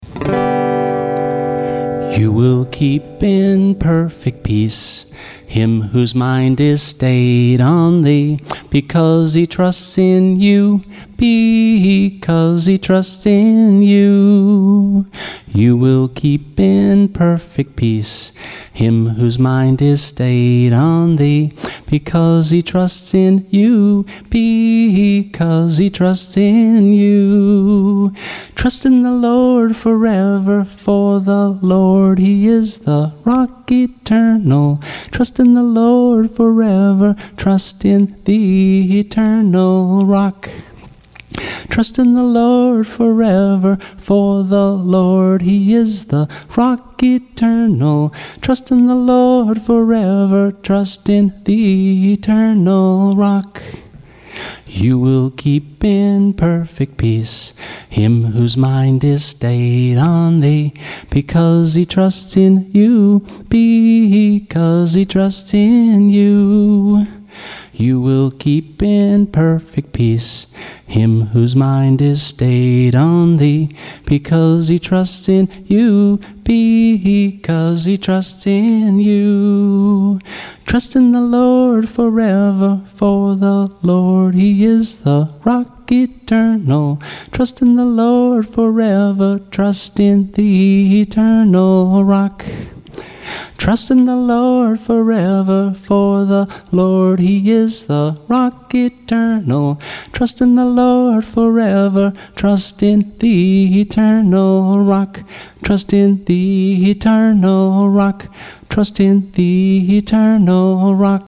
[MP3 Recording - vocal only]
[MP3 Recording - with guitar]